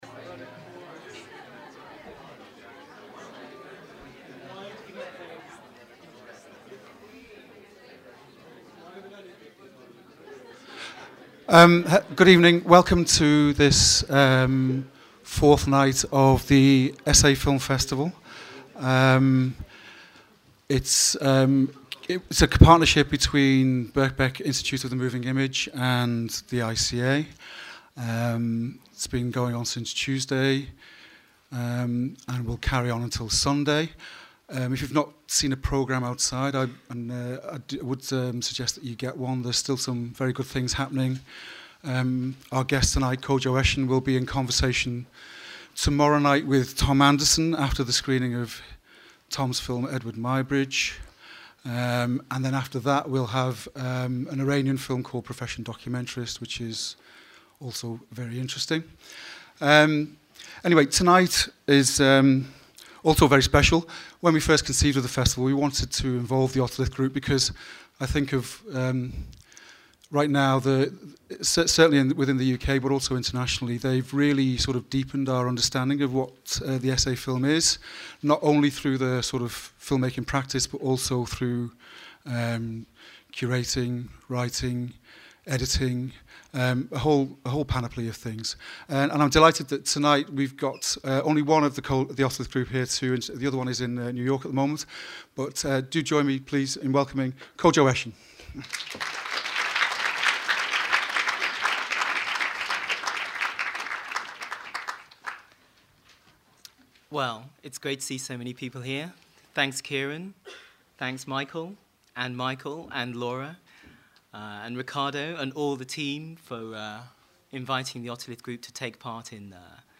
27 March 2015, 18:30, ICA Cinema
A special presentation of three video essays by The Otolith Group, introduced by Kodwo Eshun.
The Essay Film Festival ran between 2015 and 2023, and presented a global range of contemporary and restored essayistic works.